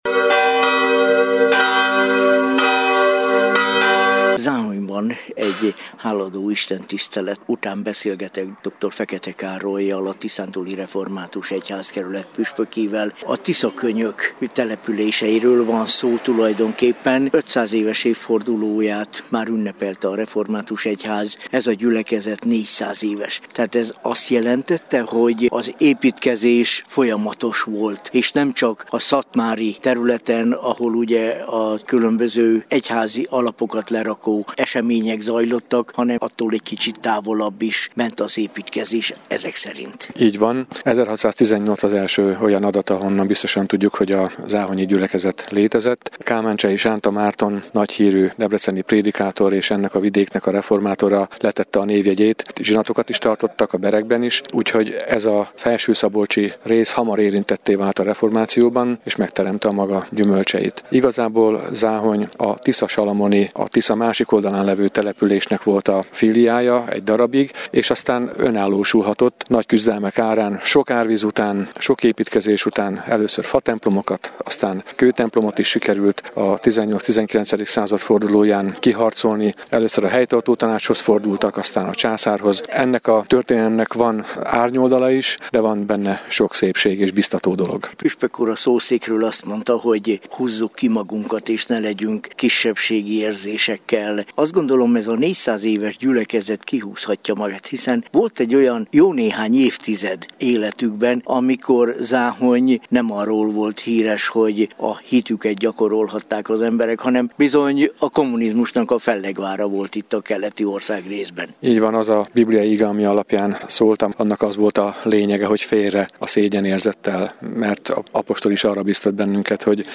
Hálaadó Istentisztelet Záhonyban